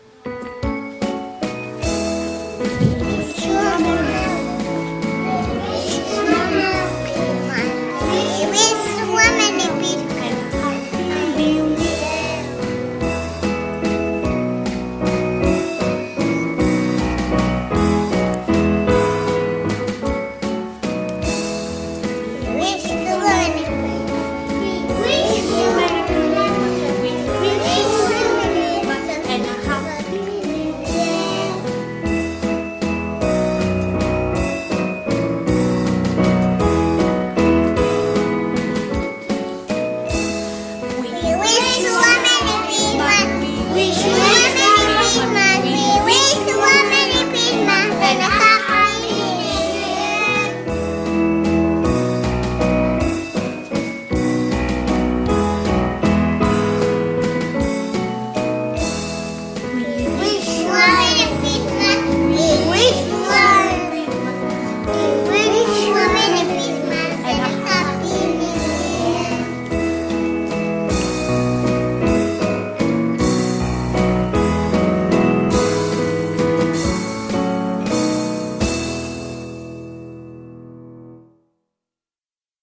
Els més petitons de l’escola van cantar la cançó “WE WISH YOU”, escolteu com canten.